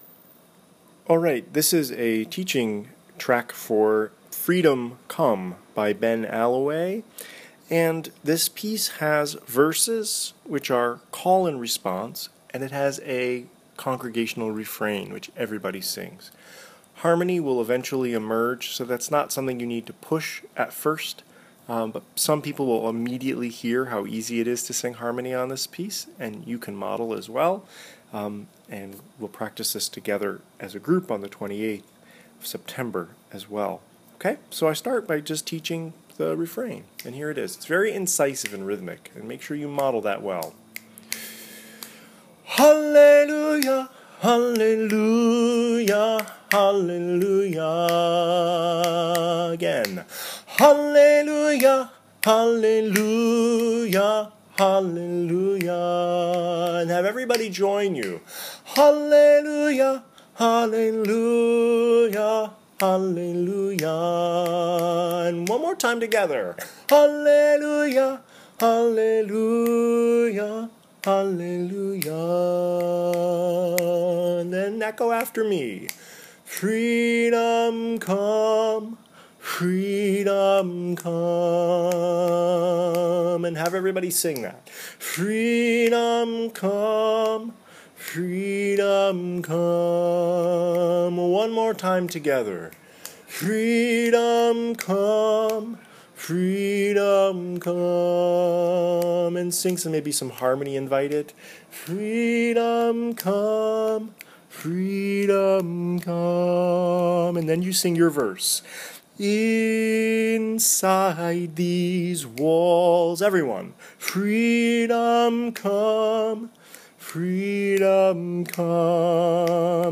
We’ll be singing this call and response piece by Ben Allaway in the Fall months as a Gathering Song at St. Lydia’s.